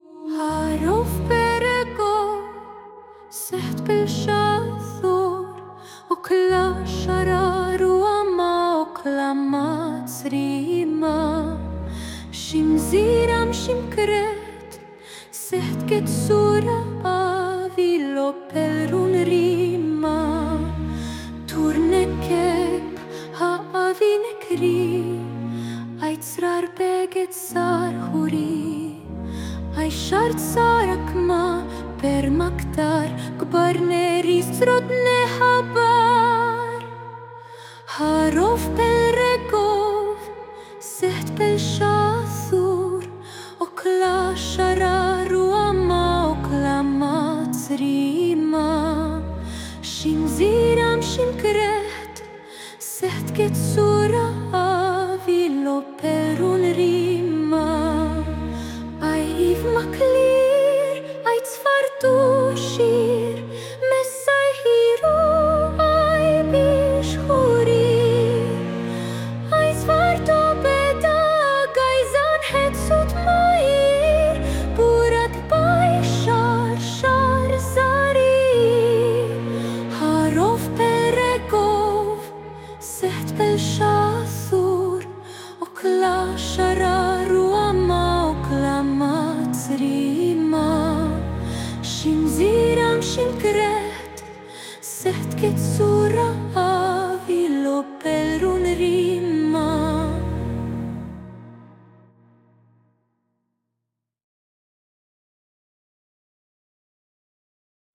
Secht_Pelshathor_female.mp3